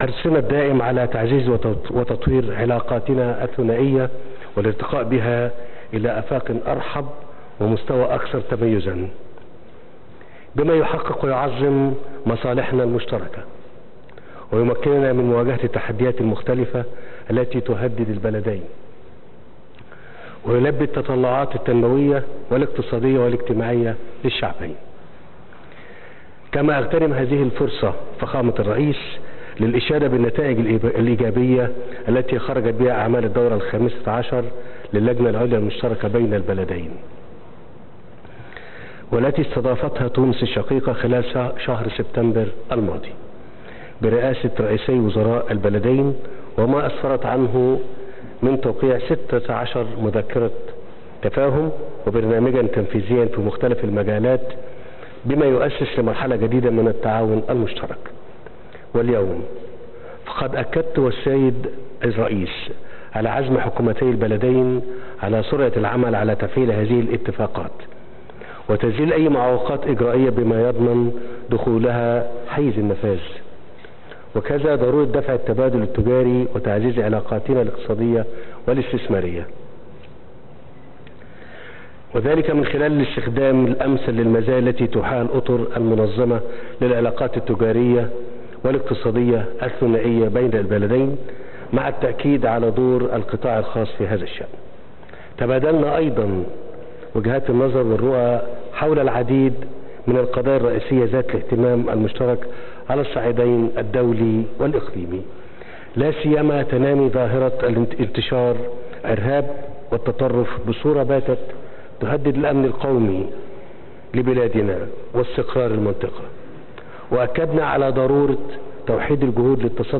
قال الرئيس المصري عبد الفتاح السيسي في كلمة ألقاها اليوم الأحد في مؤتمر صحفي مشترك مع الباجي قائد السبسي، عقب جلسة محادثات رسمية جمعتهما، إنهما اتفقا على ضرورة التوصل إلى تسوية سياسية في سوريا.